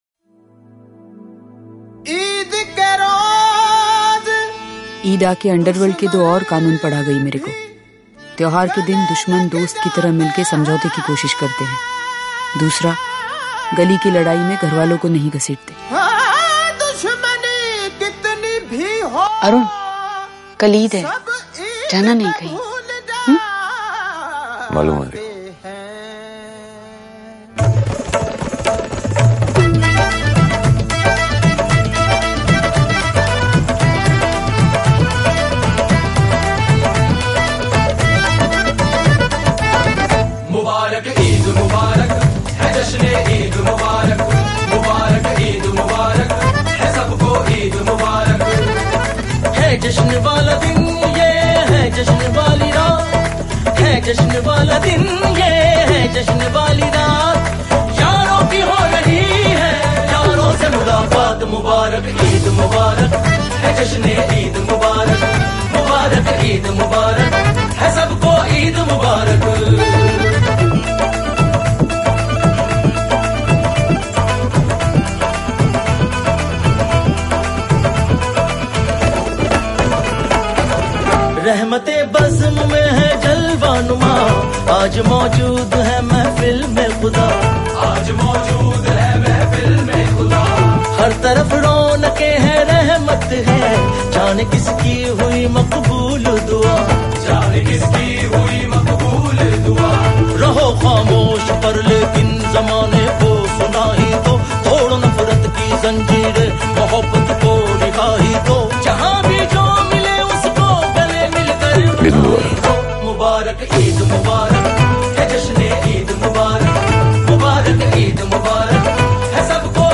Genre Latest Punjabi Songs